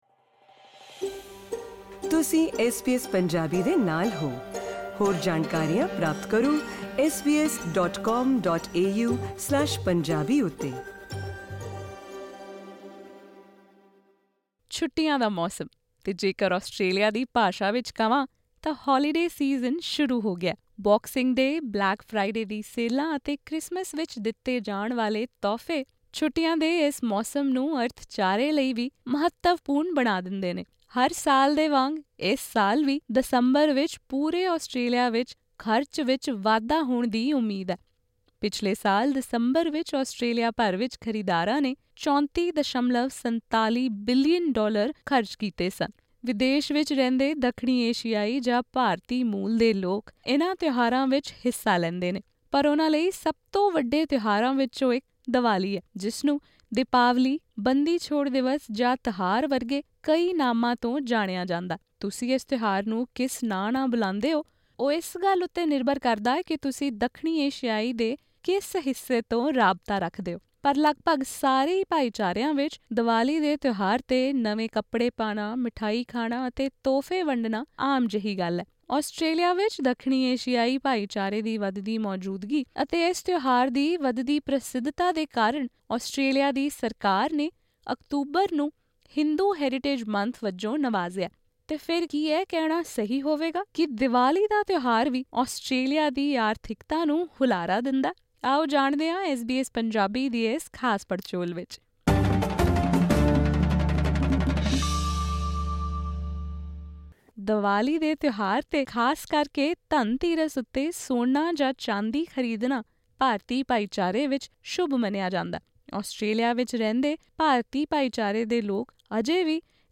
ਸੋਨੇ ਦੇ ਕਾਰੋਬਾਰੀਆਂ ਦਾ ਕਹਿਣਾ ਹੈ ਕਿ ਇਨ੍ਹਾਂ ਦਿਨਾਂ ਦੌਰਾਨ ਭਾਈਚਾਰੇ ਵਿੱਚ ਸੋਨੇ ਅਤੇ ਚਾਂਦੀ ਦੇ ਸਿੱਕਿਆਂ ਦੀ ਮੰਗ ਜ਼ਿਆਦਾ ਰਹਿੰਦੀ ਹੈ। ਸੁਣੋ ਪੂਰੀ ਗੱਲਬਾਤ ਇਸ ਪੋਡਕਾਸਟ ਰਾਹੀਂ.......